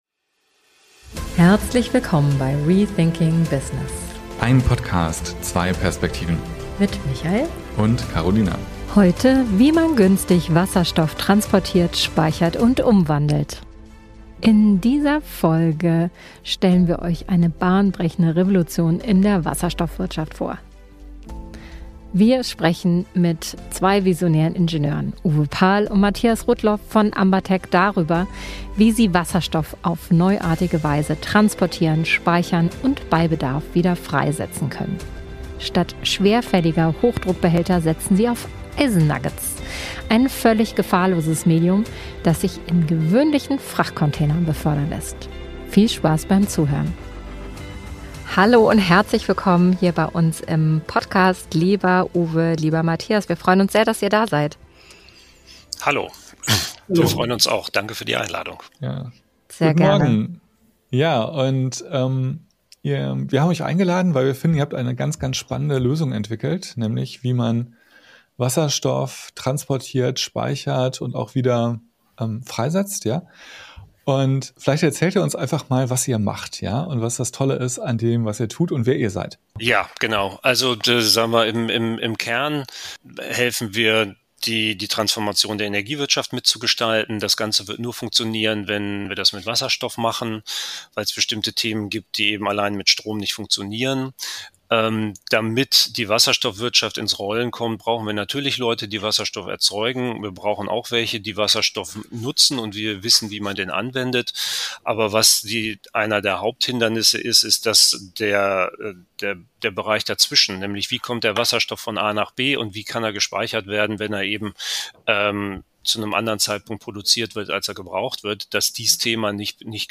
In dieser Folge sprechen wir mit zwei visionären Ingenieuren darüber, wie sie Wasserstoff auf neuartige Weise transportieren, speichern und bei Bedarf wieder freisetzen können.